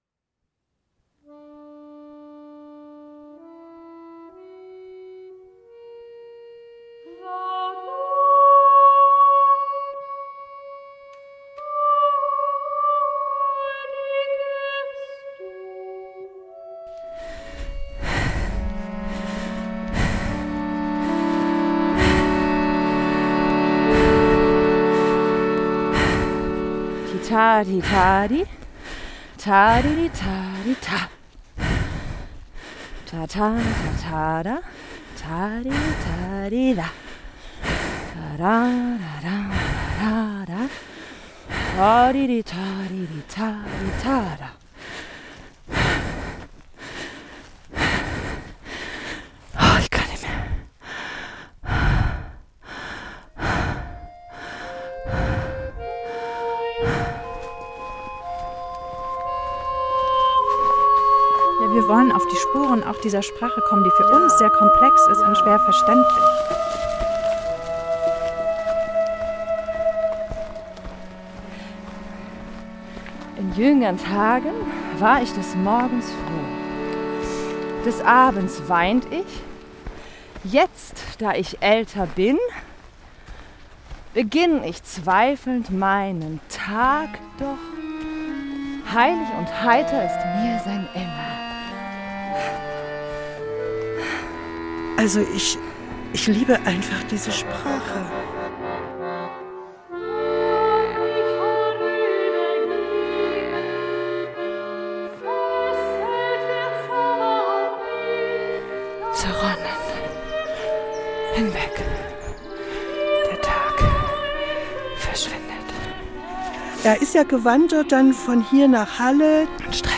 Ein literarischer Konzertabend
Stimme
Akkordeon